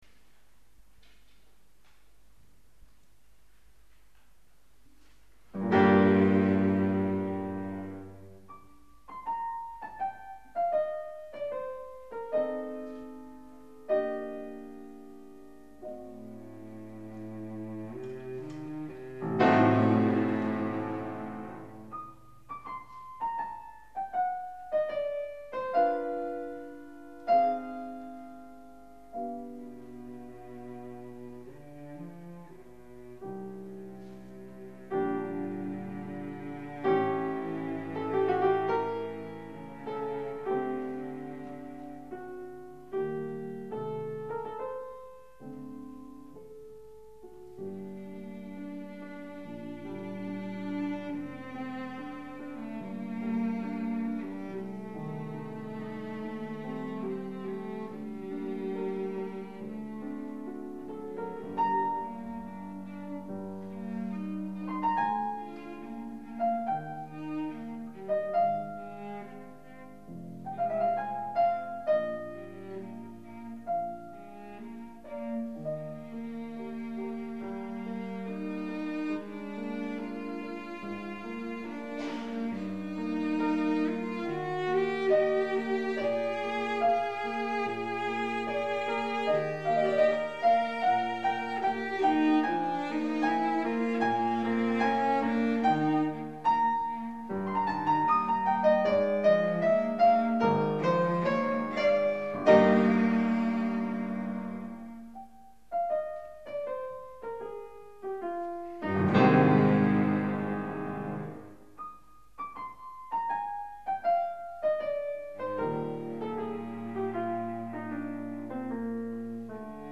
violoncello
piano) Piano: Bösendorfer Gewerbesaal, Mödling
Ludwig van Beethoven (1770 - 1827): Sonata for piano and violoncello in g minor, op. 5/2 (1796) [22'] Adagio sostenuto e espressivo - Allegro molto più tosto presto Rondo.